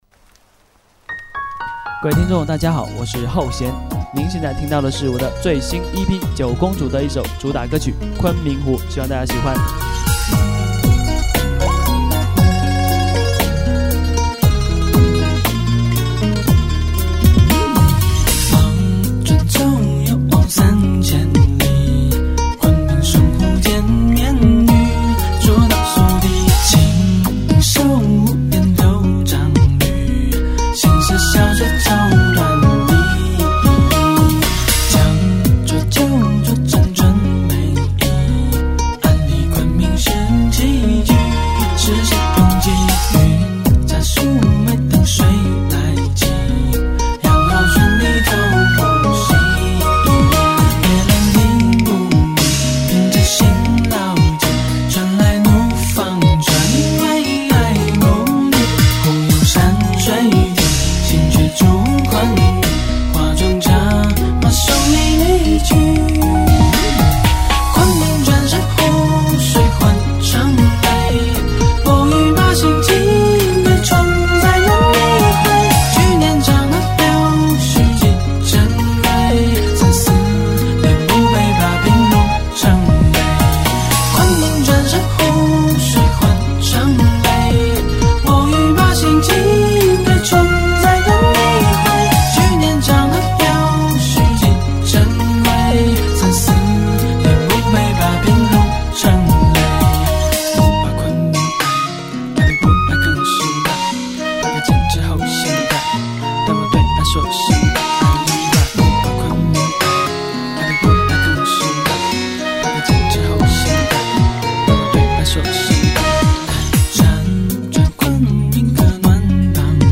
音乐很简单，琅琅上口，配乐非常独特，很有味道。